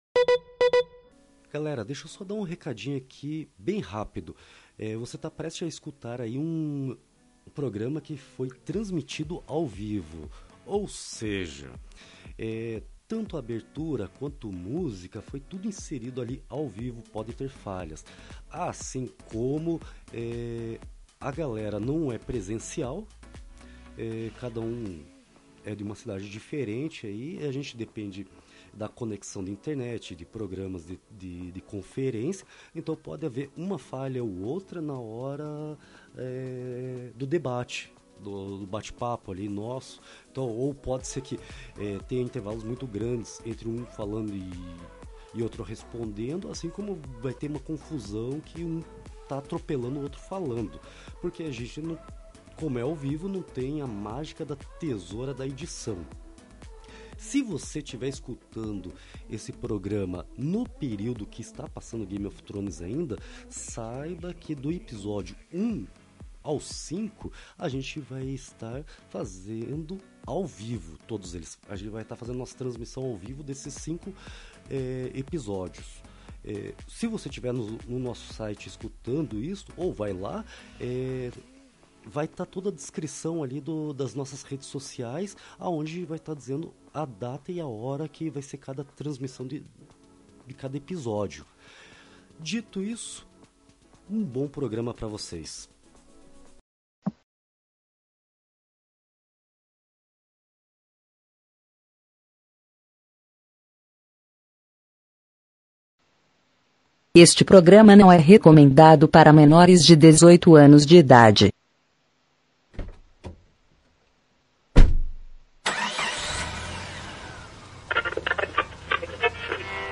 Hoje estamos ao vivo para debater nossas impressões sobre o primeiro episódio da última temporada de Game of Thrones.